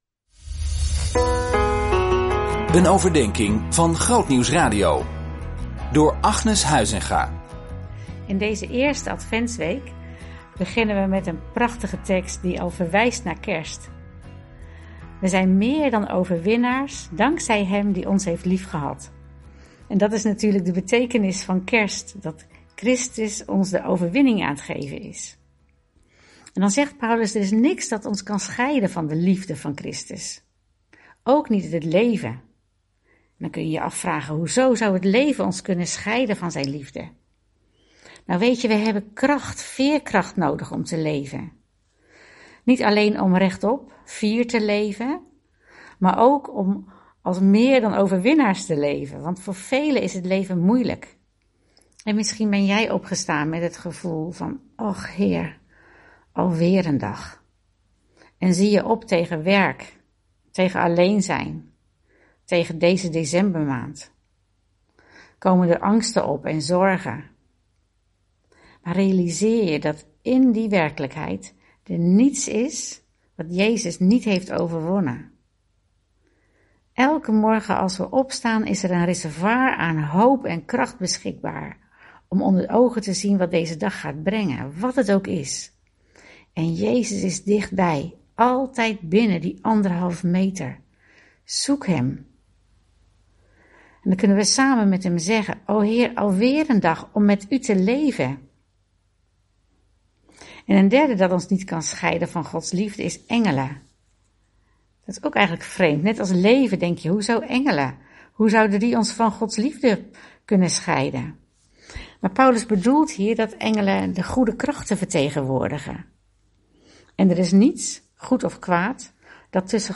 Devotional Reading Plan